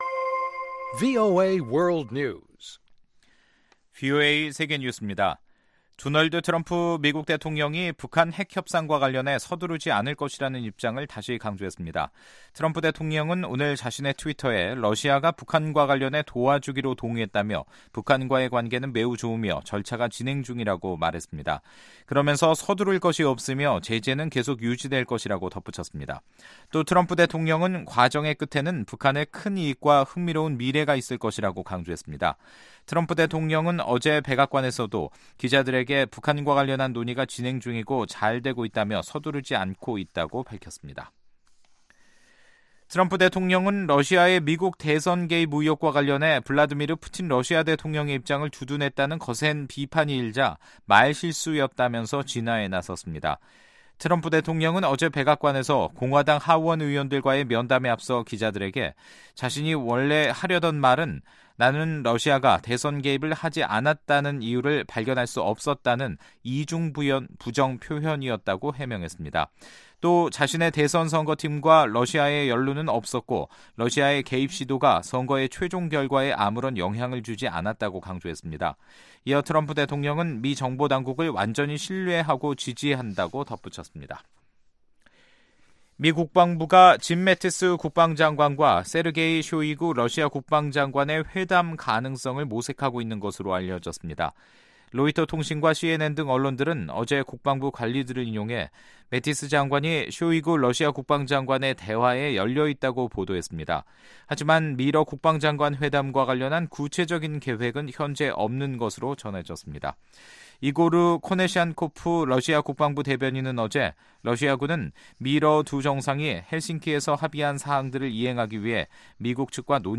VOA 한국어 간판 뉴스 프로그램 '뉴스 투데이', 2018년 7월 18일 3부 방송입니다. 트럼프 미국 대통령은 북한 핵 문제와 관련해 제한 시간을 두지 않겠다고 말했습니다. 미 상원 군사위원회 소속 의원들은 북한의 구체적 비핵화 조치가 선결돼야 한국전쟁 종식을 선언할 수 있다는 입장을 밝혔습니다.